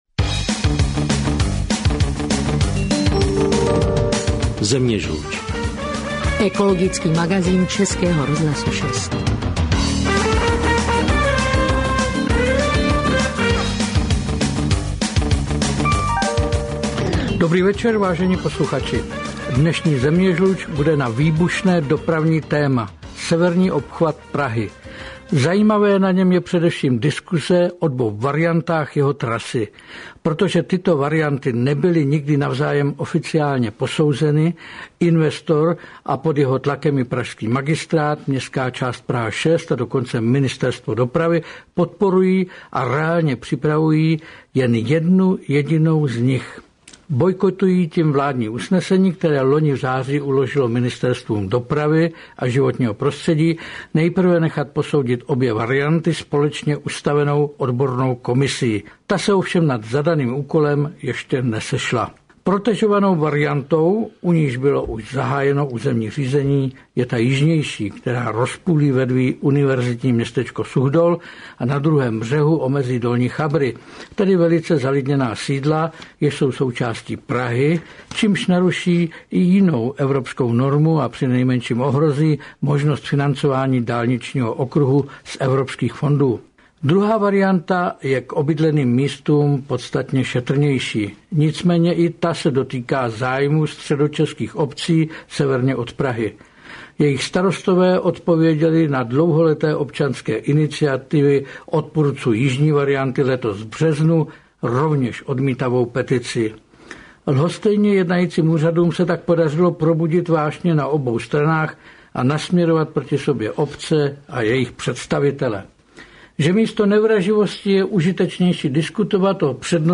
Rozhovor s Ing.